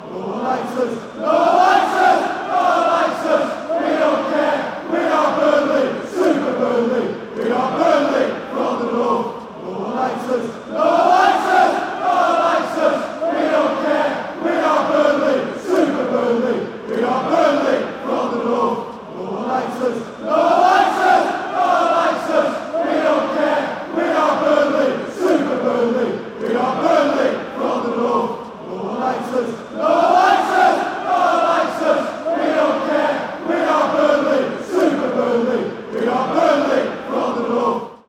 A BFC soccer chant.